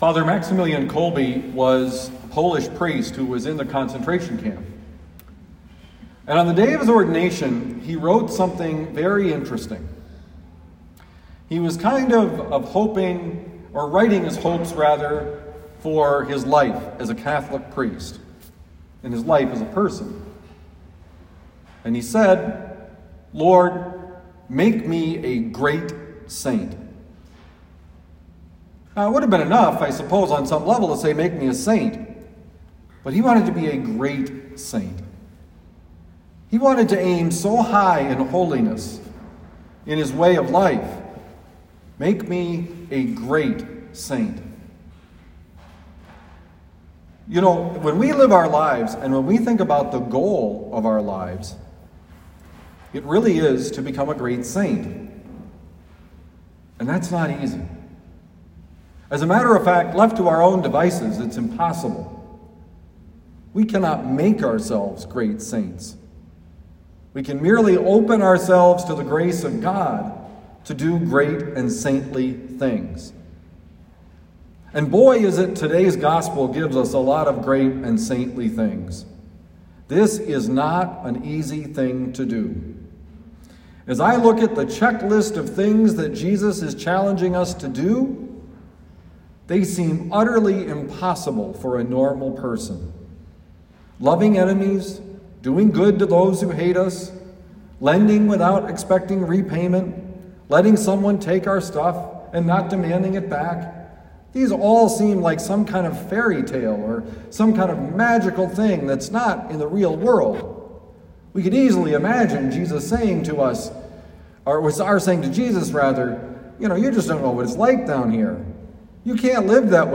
Homily given at Our Lady of Lourdes Parish, University City, Missouri.